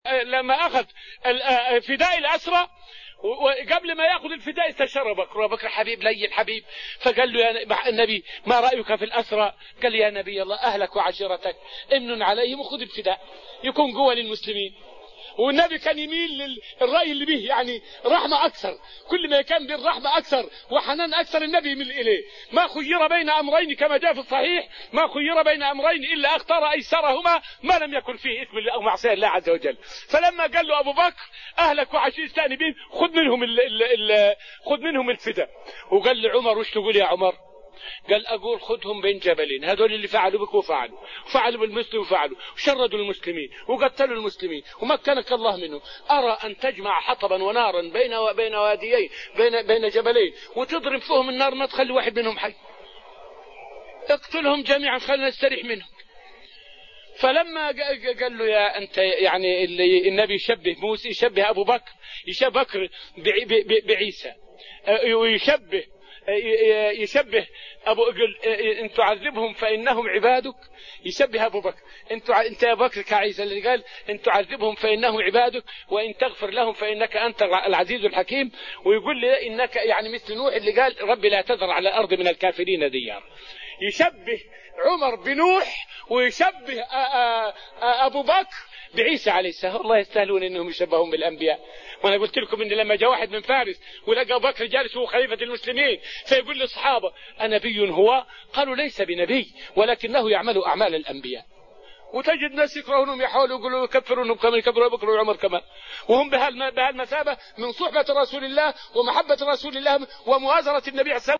فائدة من الدرس الثامن من دروس تفسير سورة الأنفال والتي ألقيت في رحاب المسجد النبوي حول تشبيه النبي عليه الصلاة والسلام لأبي بكر بسيدنا عيسى.